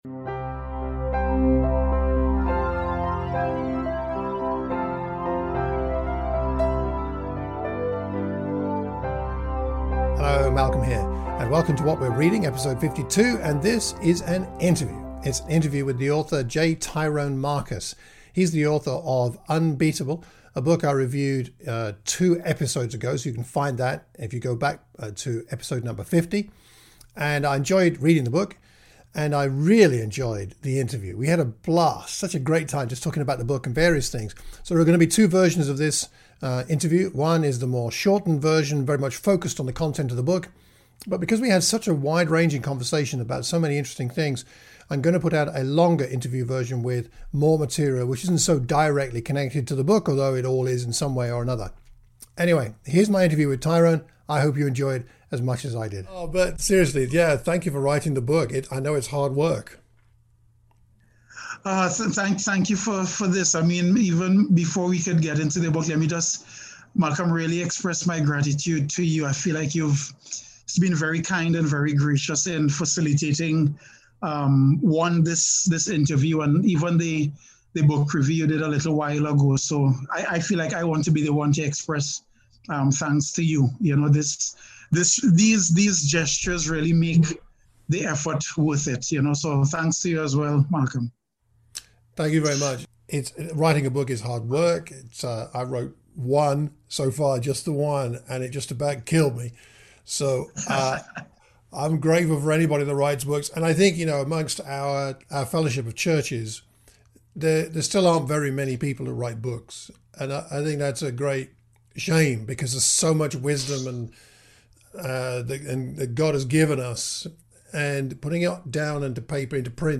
Today I bring you an interview with my new friend